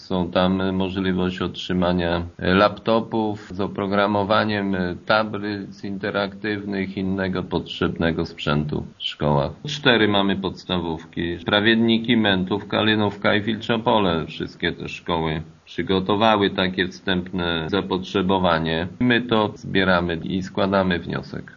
Wójt gminy Głusk Jacek Anasiewicz wierzy, że będą to dobrze wydane pieniądze, dzięki którym wzrosną wyniki kształcenia: